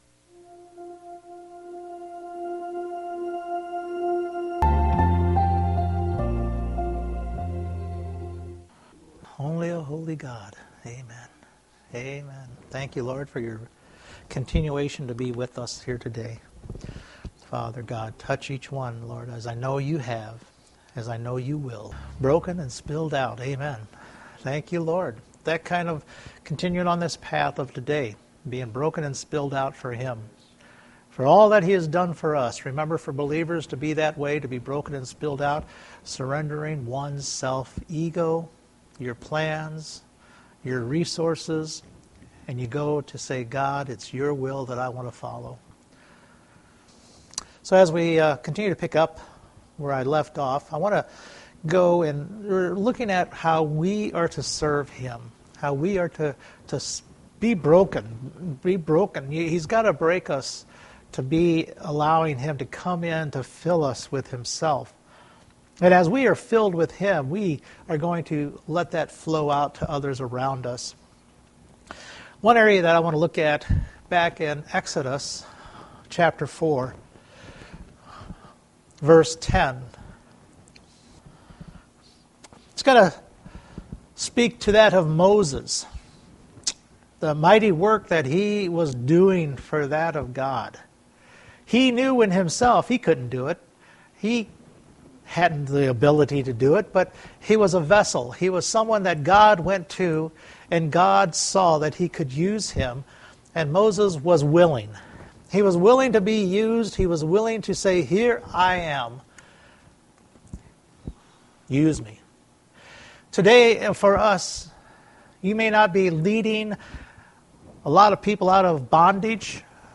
Several Service Type: Sunday Morning Looking at ourselves being broken for Jesus and how Jesus broke and spilled out for us.